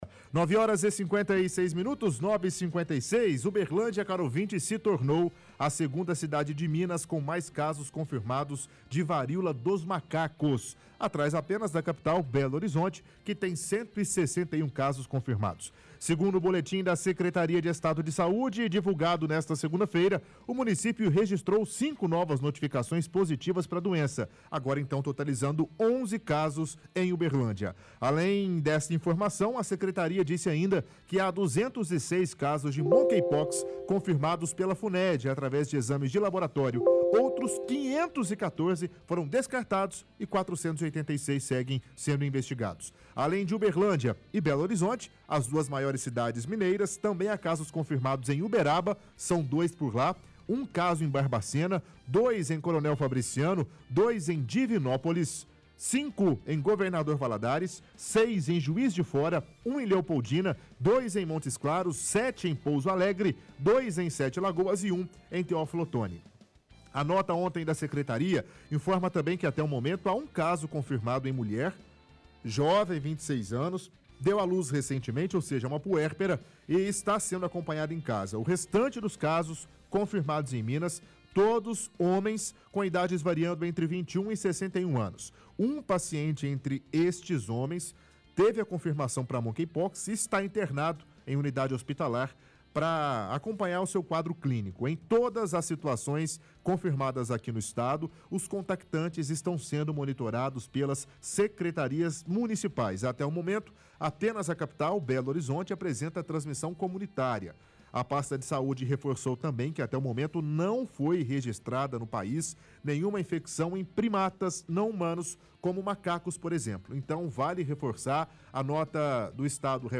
– Leitura de reportagem do Diário de Uberlândia sobre a confirmação de 11 casos no município.